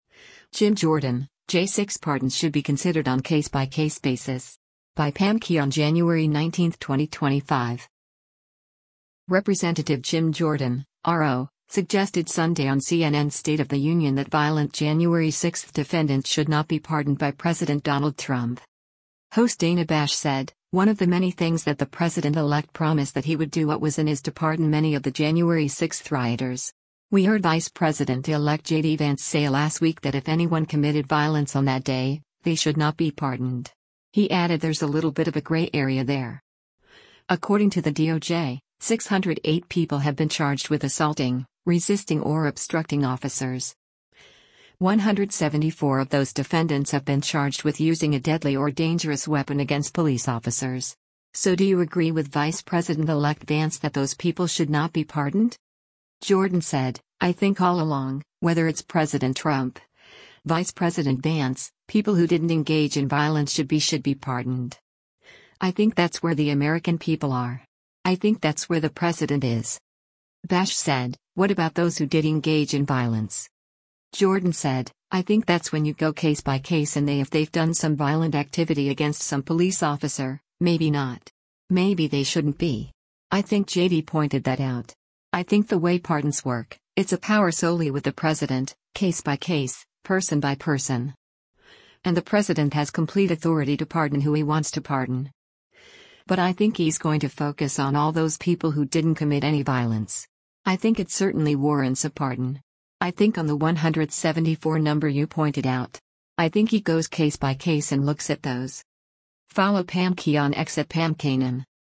Representative Jim Jordan (R-OH) suggested Sunday on CNN’s “State of the Union” that violent January 6 defendants should not be pardoned by President Donald Trump.